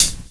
HH 28.wav